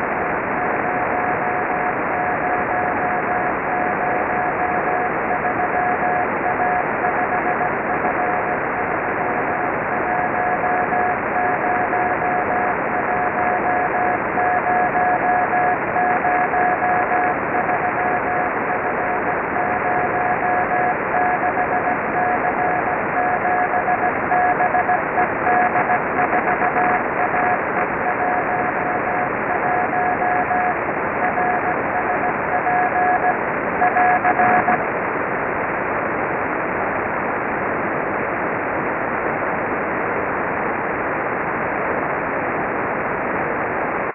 The satellite tumbles with a period of about 10 seconds.
While the stereo representation of H and V polarization makes the fading very intuitive and easy to understand by ear, we are slightly concerned that it might lead to technical misunderstandings if not properly explained.
rsp03-finalpass-fading.ogg